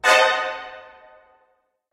Звуки скримера, неожиданности
Есть такой резкий скрипач